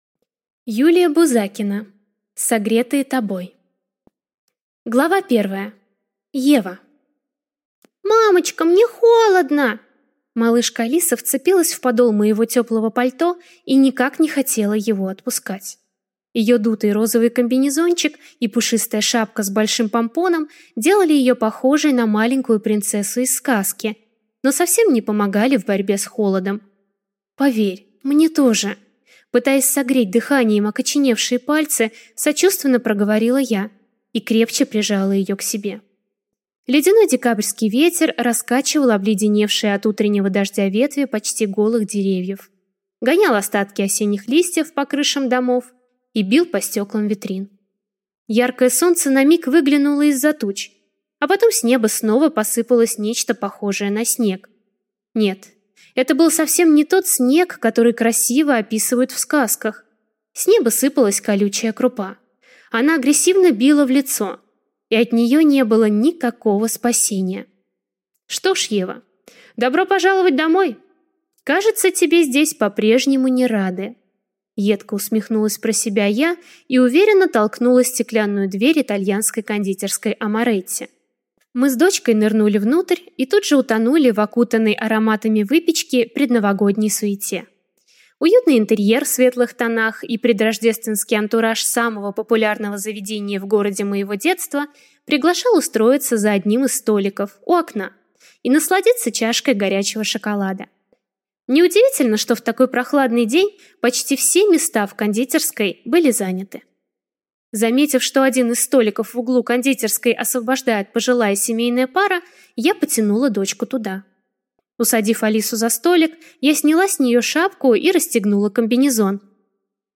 Аудиокнига Согретые тобой | Библиотека аудиокниг